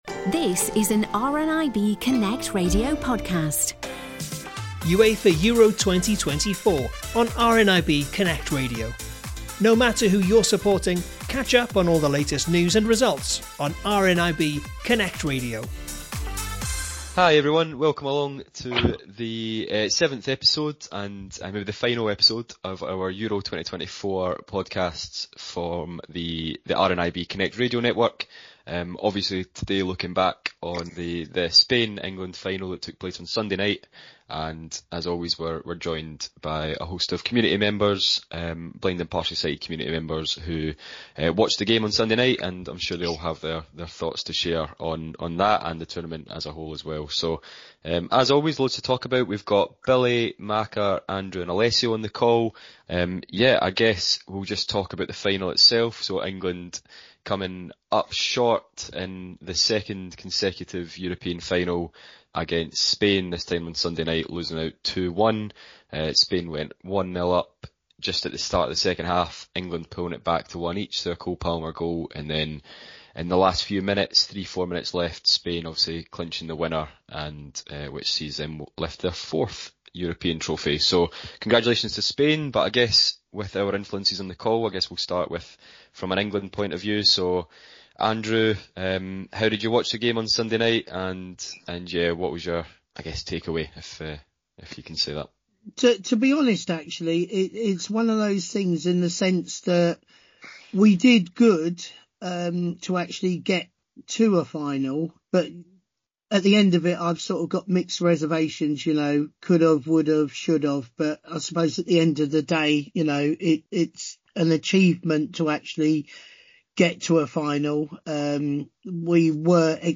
Football-loving members of RNIB's peer support groups are getting together to discuss highlights from Euro 24. This time, they’re looking back on Spain’s win and England’s future.